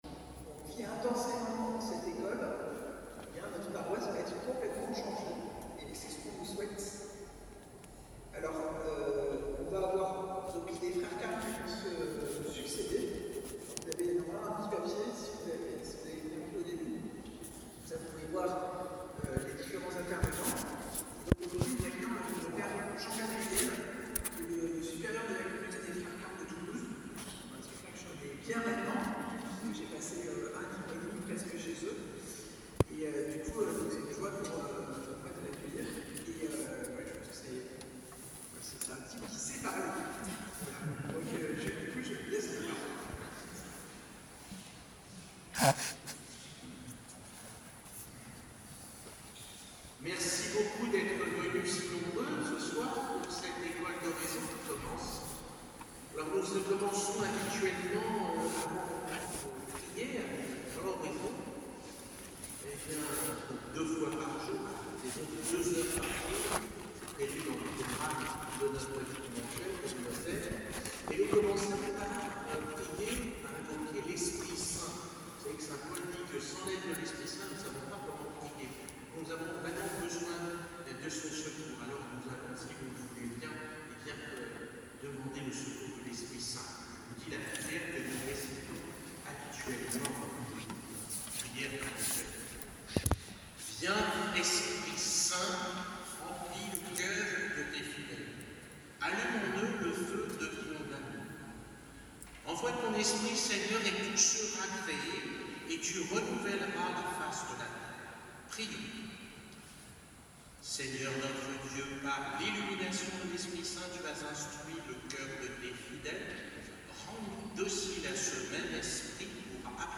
Conférence de carême